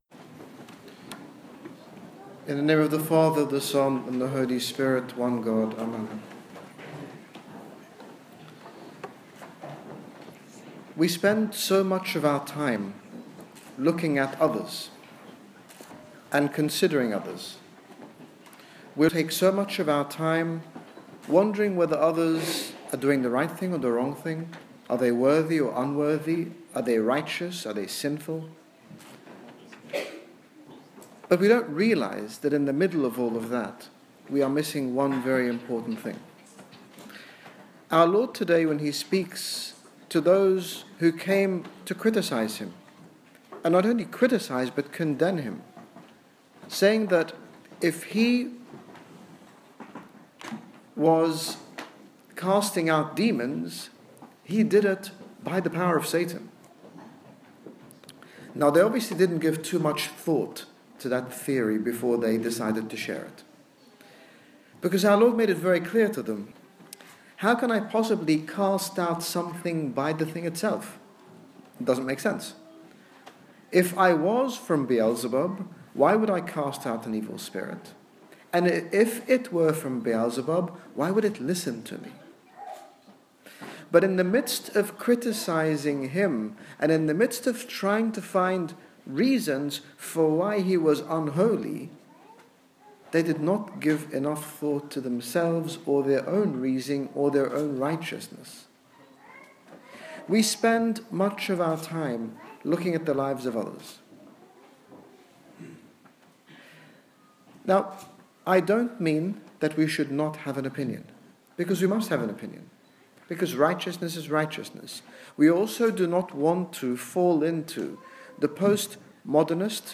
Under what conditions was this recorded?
Called to love, not judge - Sermon in Melbourne Australia